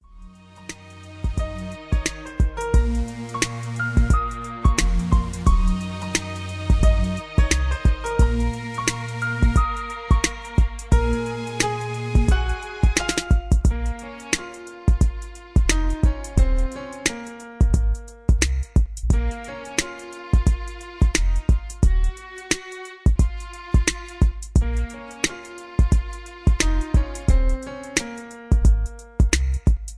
Good Beat to use in a drama or suspense scene